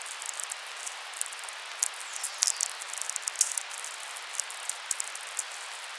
Radio Observatory